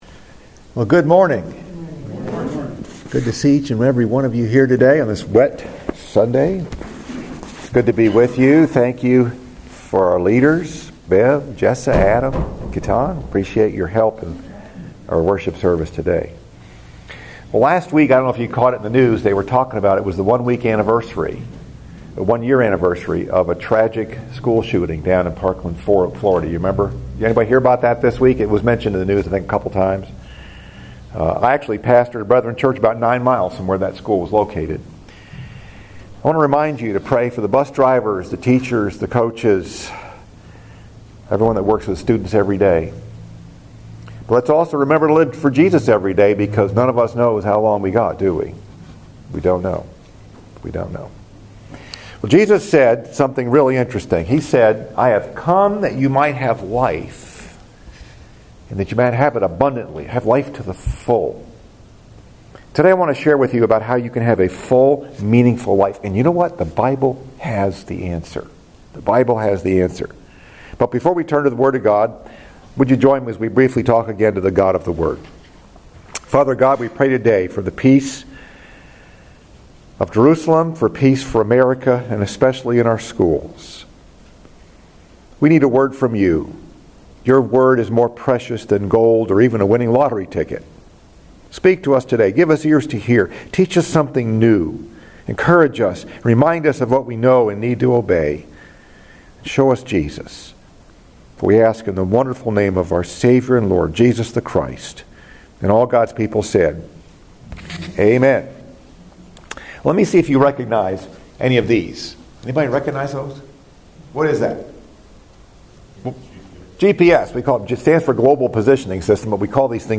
Message: “How To Have A Full Life” Scripture: John 10:10, Matthew 25:14-30 EIGHTH SUNDAY AFTER CHRISTMAS